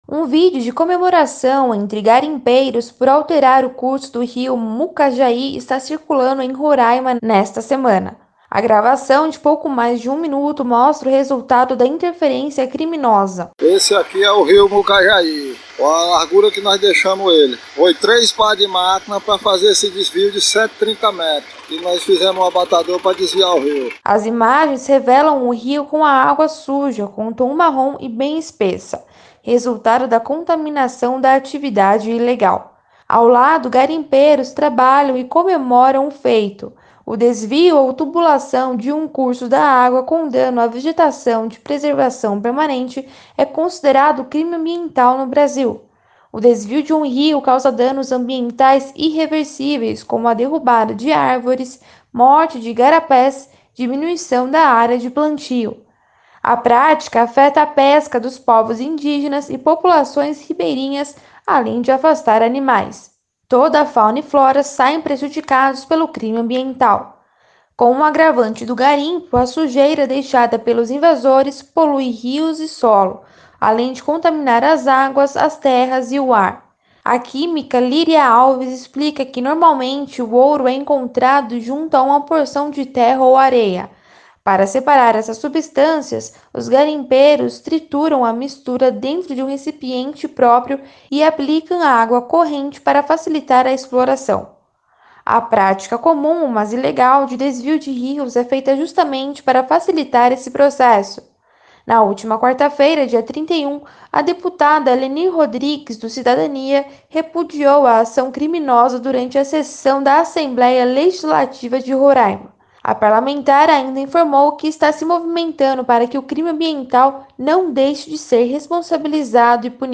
Um vídeo de comemoração entre garimpeiros por alterar o curso do Rio Mucajaí está circulando em Roraima nesta semana. A gravação de pouco mais de um minuto mostra o resultado da interferência criminosa.